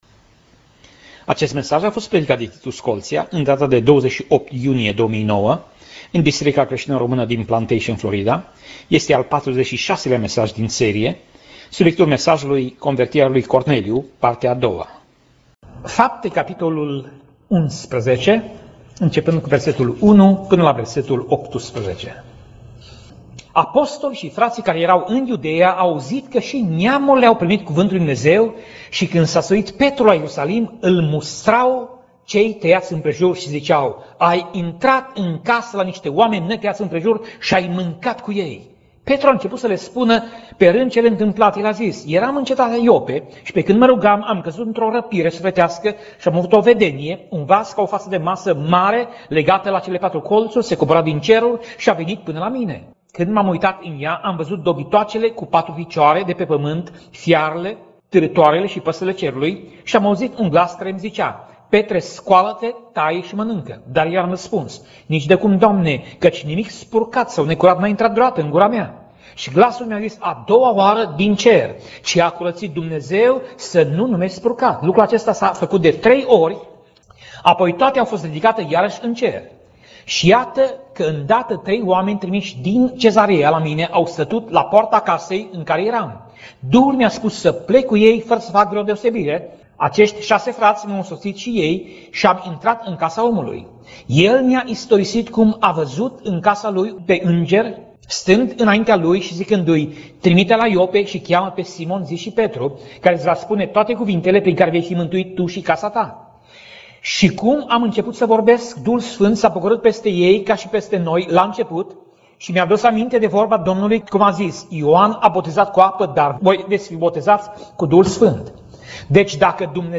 Pasaj Biblie: Faptele Apostolilor 10:1 - Faptele Apostolilor 10:46 Tip Mesaj: Predica